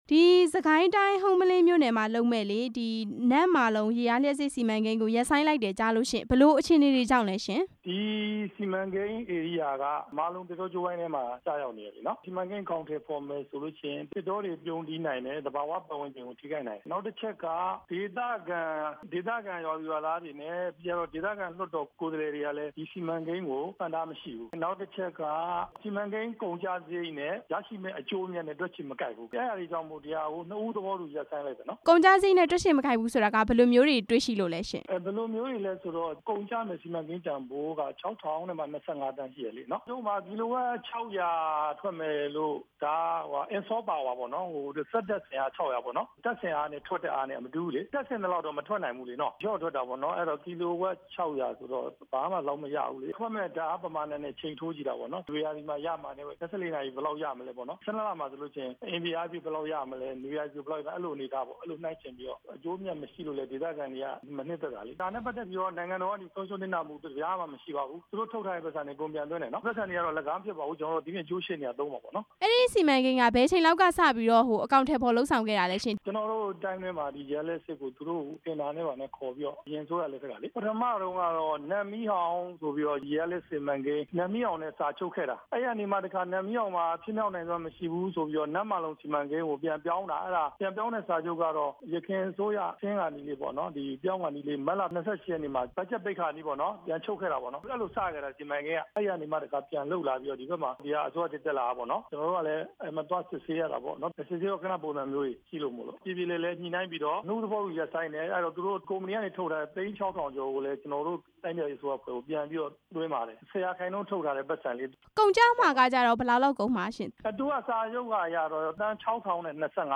နမ့်မာလုံ ရေအားလျှပ်စစ်စီမံကိန်းအကြောင်း မေးမြန်းချက်